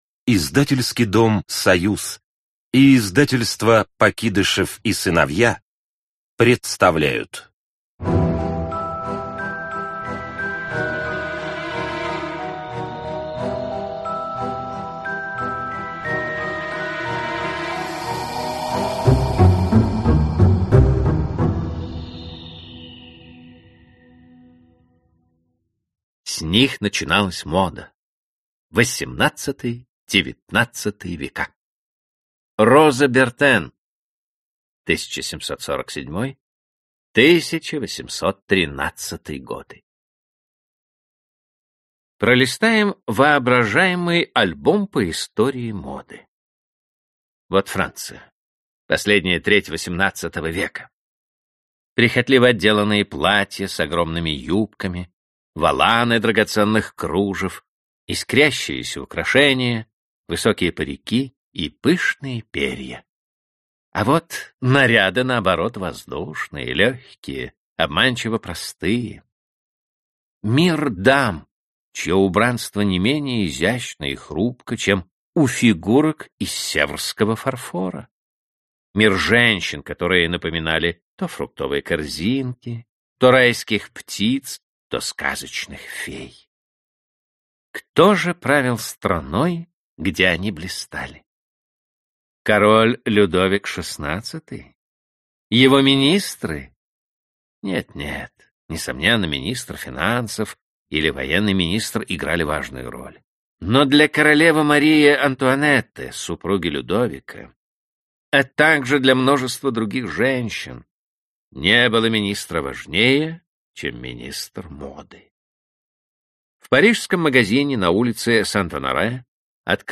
Аудиокнига Великие творцы моды | Библиотека аудиокниг